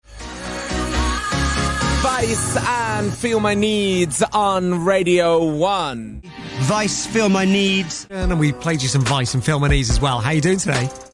読み方
ヴァイス
BBC Radio1 Nick Grimshaw、Matt Edmondson、Scott Millsの発音